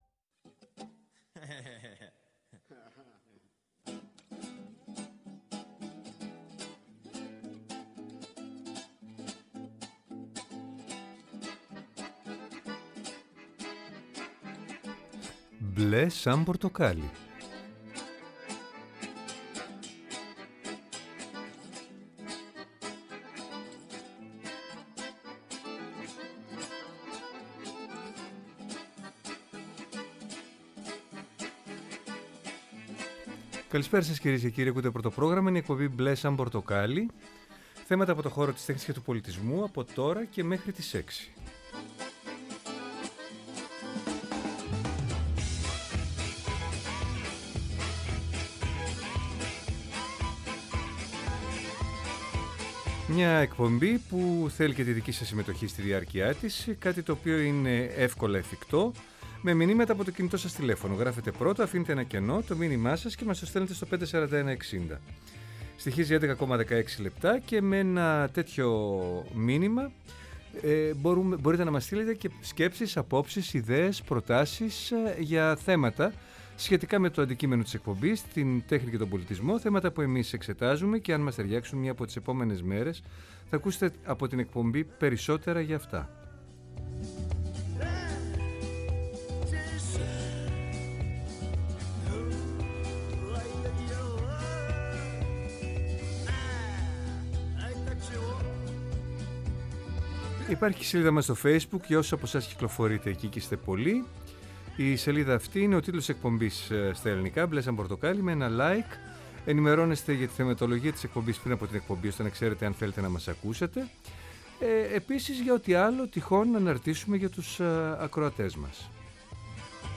Μια εκπομπή με εκλεκτούς καλεσμένους, άποψη και επαφή με την επικαιρότητα.